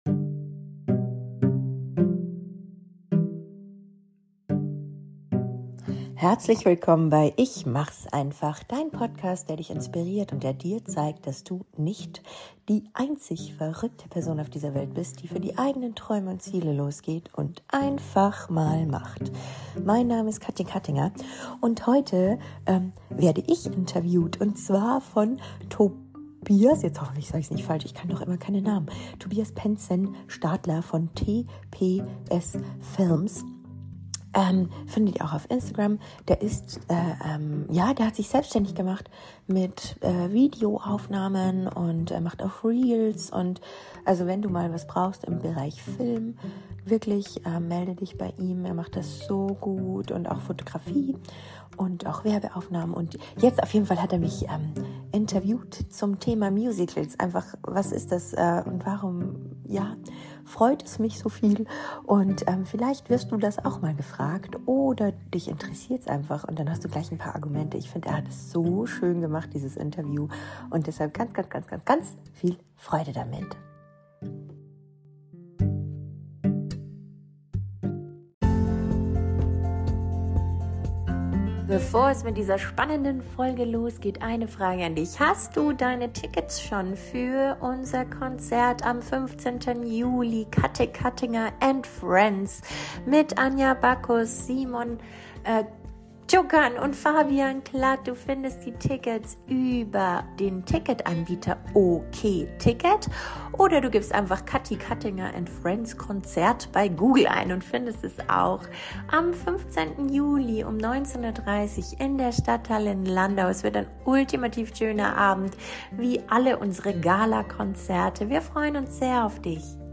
65) Was sind Musicals? (Interview